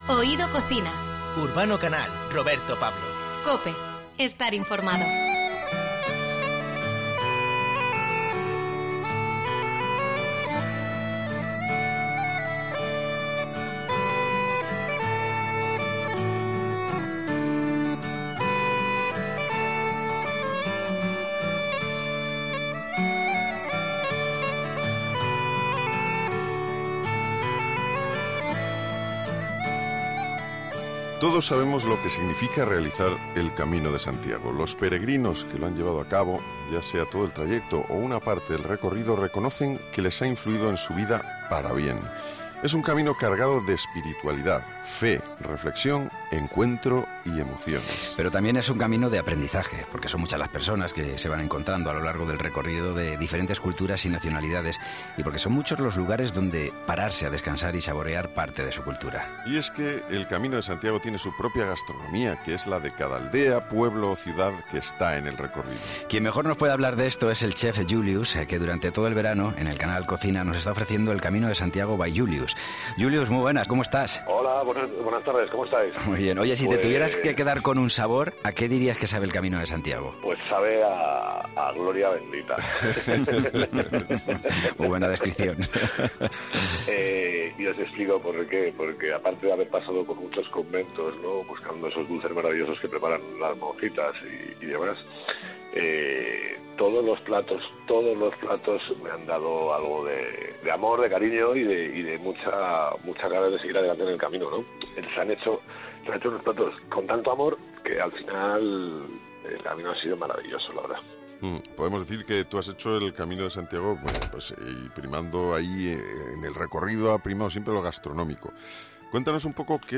Hablamos con el popular cocinero para que nos cuente su experiencia.